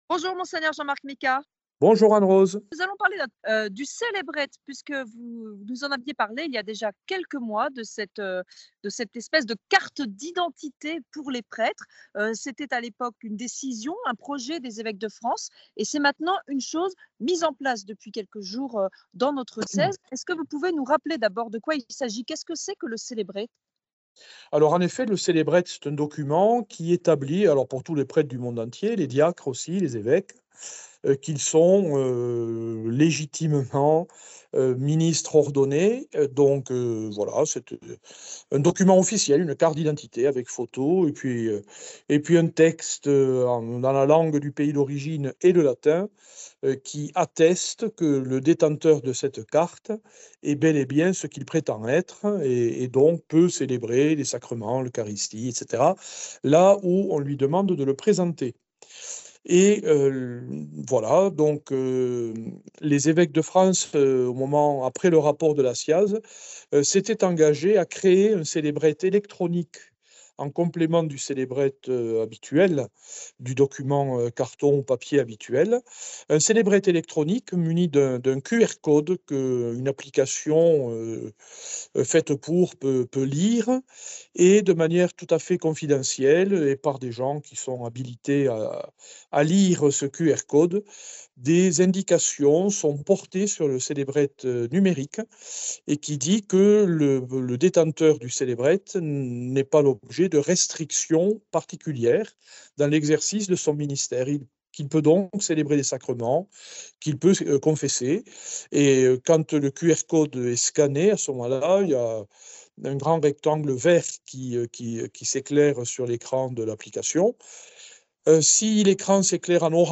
Dans le grand entretien régional de cette semaine, Mgr Jean-Marc Micas nous en apprends plus aujourd’hui sur le célébret.